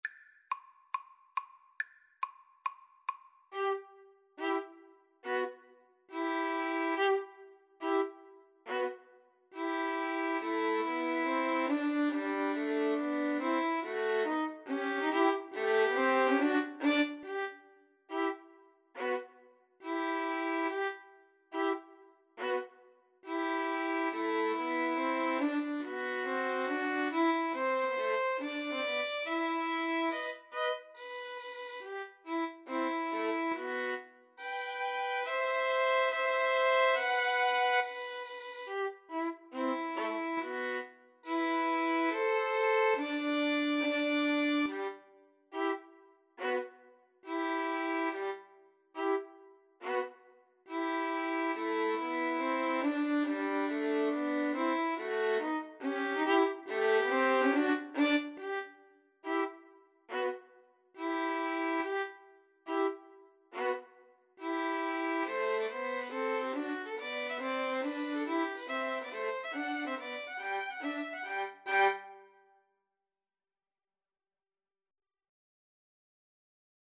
G major (Sounding Pitch) (View more G major Music for Violin Trio )
Tempo di marcia =140
Classical (View more Classical Violin Trio Music)